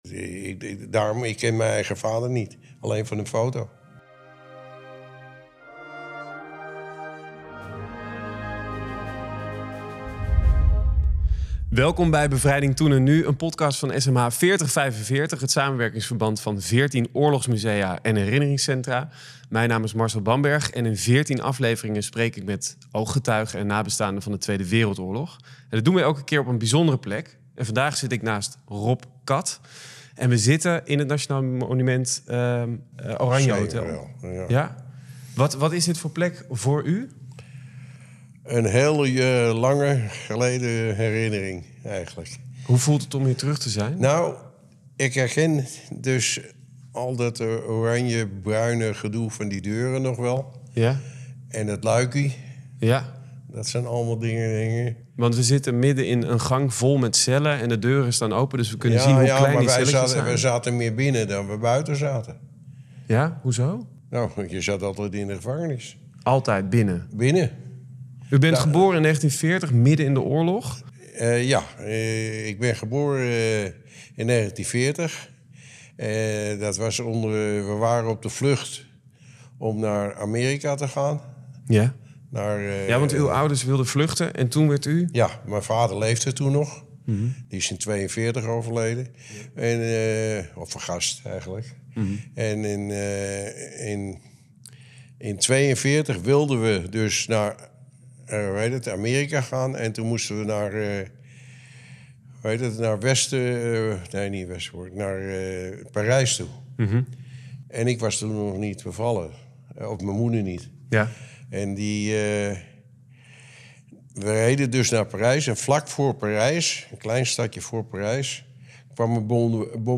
15:27 Wandeling door het Oranjehotel